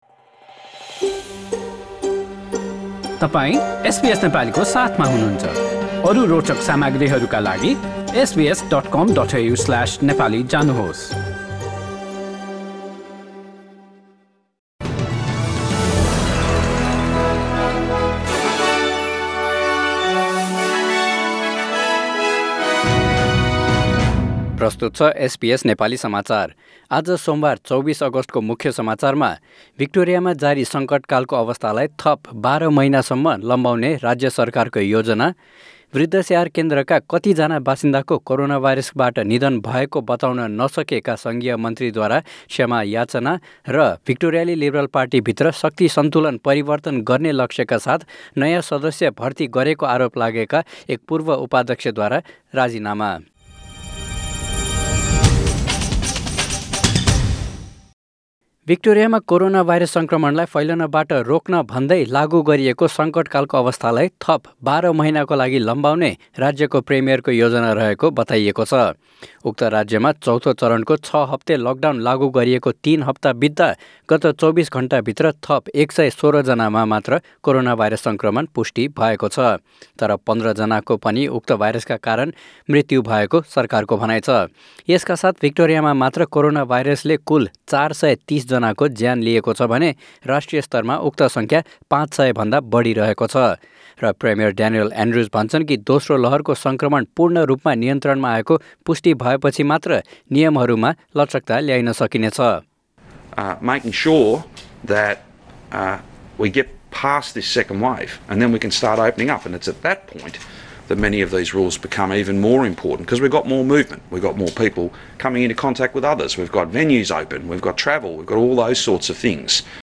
एसबीएस नेपाली अस्ट्रेलिया समाचार: सोमवार २४ अगस्ट २०२०